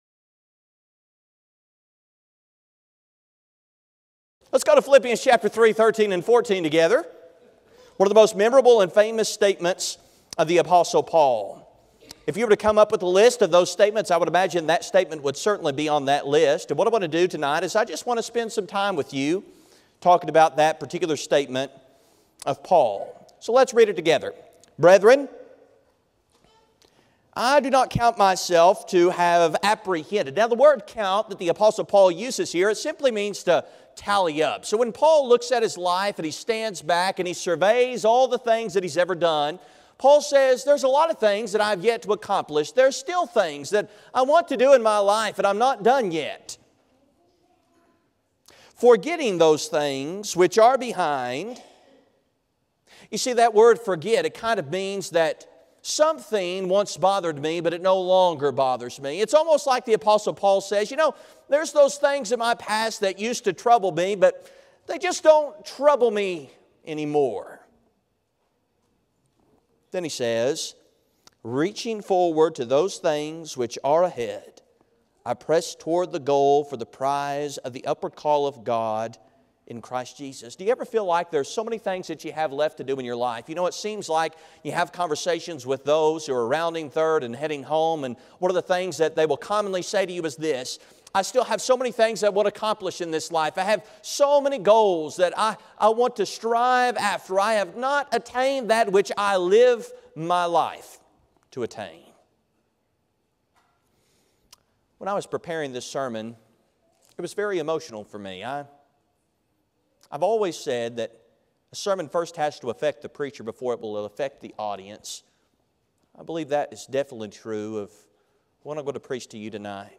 The sermon is from our live stream on 10/20/2024